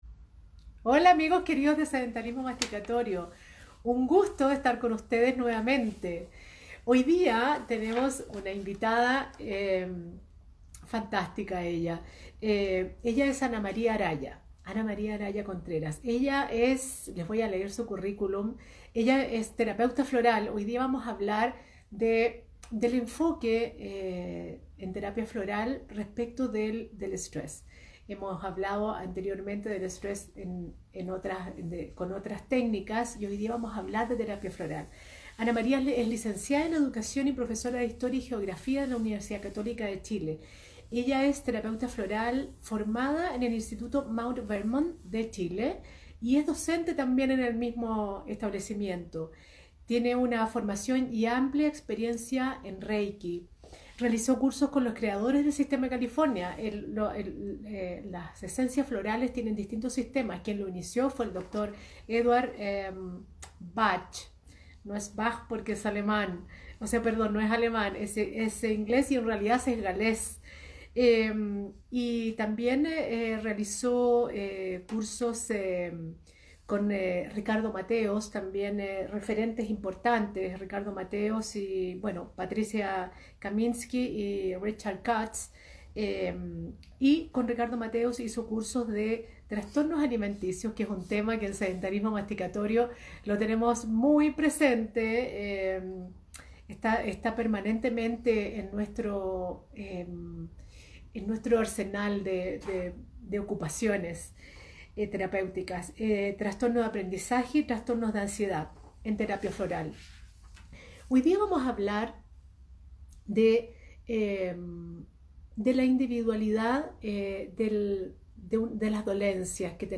¿Cómo controlar el estrés? Es el tópico de esta entrevista de Sedentarismo Masticatorio, abordaremos el dominio del estrés desde la mirada de la medicina complementaria.